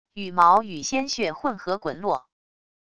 羽毛与鲜血混合滚落wav音频